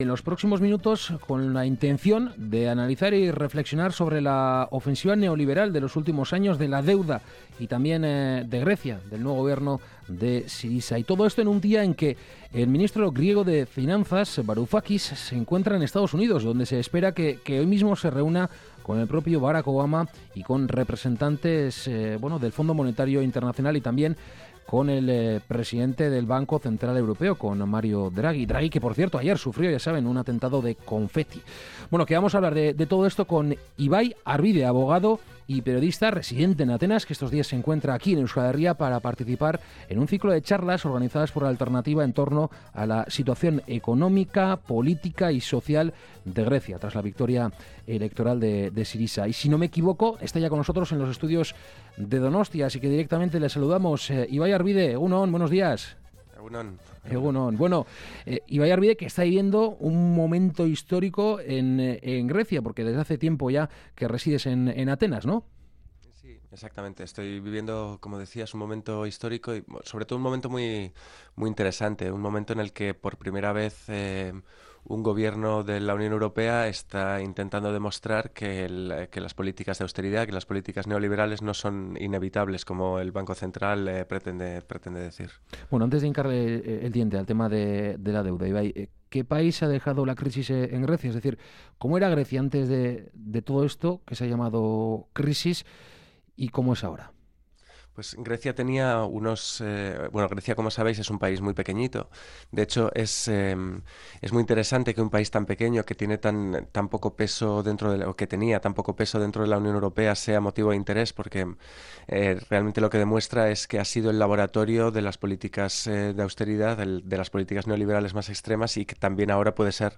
Análisis y entrevista